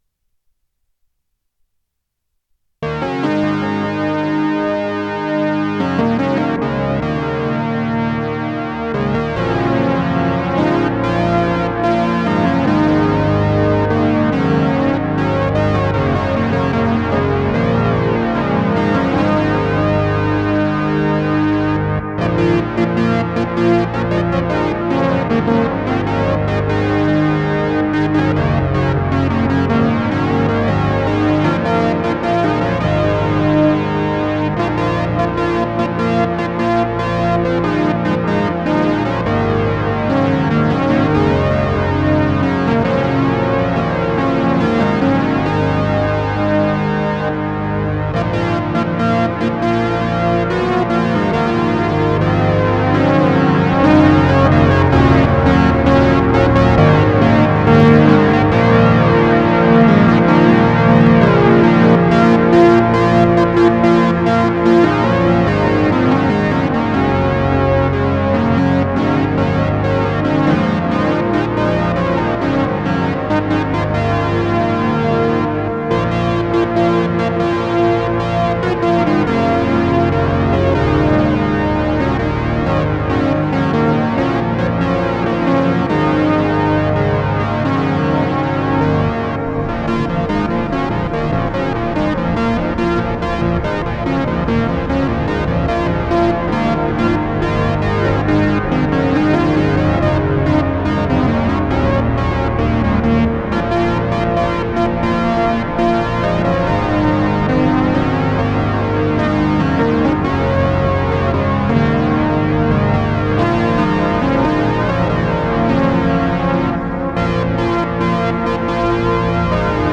Keyboard Jam Session: US_election_results_KeyboardJamSession2016
It uses synth and some fx such as echo and reverb/modulation.The patch is a Fifths patch (1 DCO at 0, the other downtuned a fifth) to give a regal, troubador, sound, it's doubled on the second channel with a -12 semitones pitch shift to add some weight.